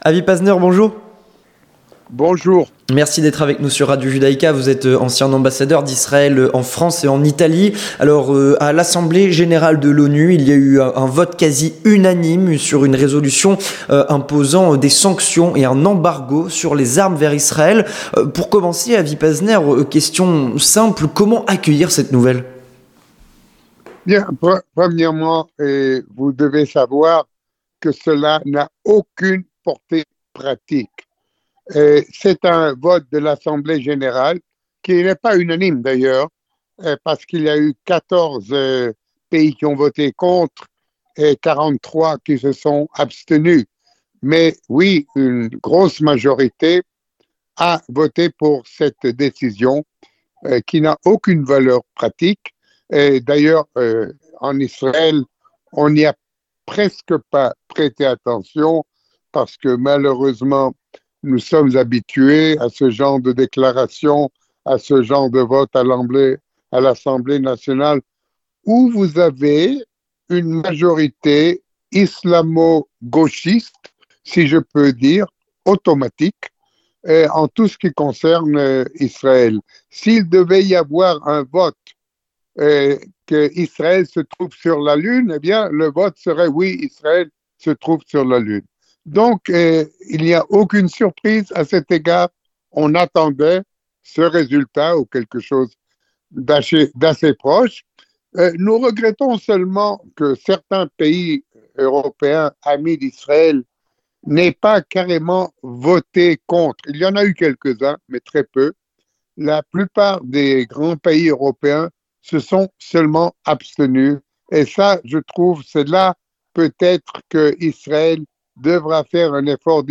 L'entretien du 18h
Avec Avi Pazner, ambassadeur d'Israel en France et en Italie